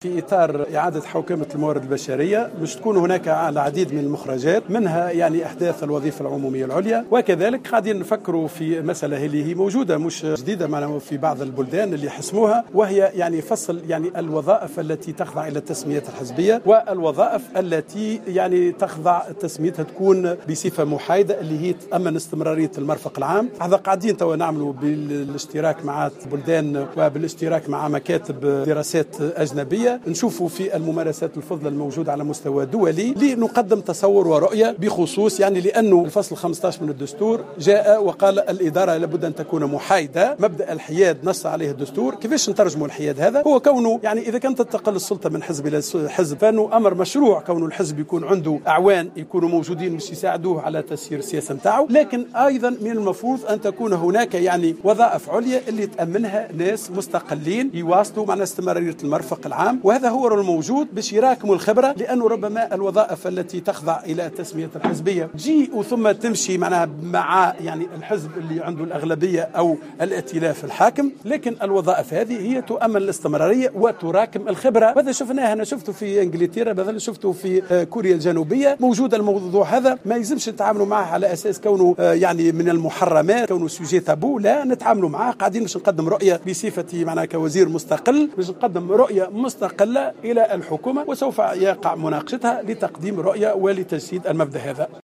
وقال كمال العيادي في تصريح لـ "الجوهرة أف أم" إنه سيتم إحداث الوظيفة العمومية العليا يؤمنها أشخاص يتسمون بالاستقلالية والحيادية فيما سيتم فصل هذه الوظيفة عن الوظائف التي تخضع لتسميات حزبية.